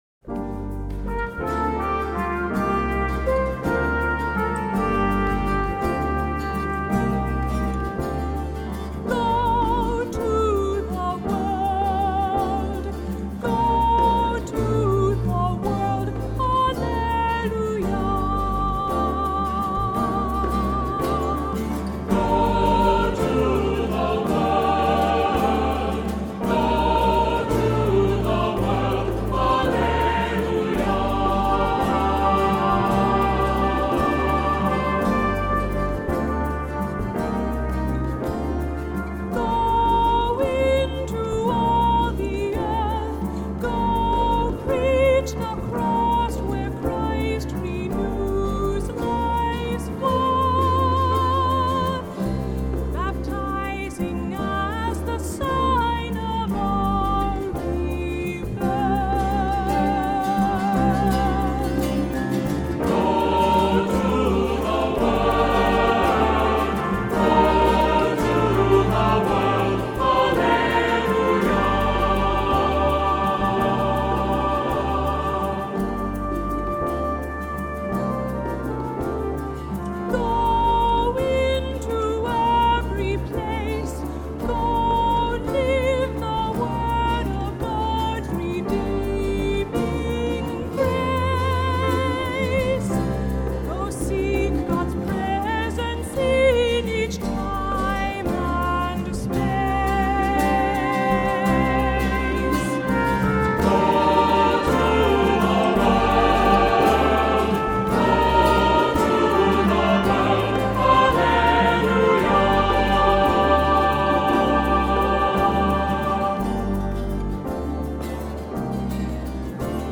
Accompaniment:      Keyboard, Trumpet in B-flat;Trumpet in C
Music Category:      Christian
This dancelike setting
Trumpet part optional.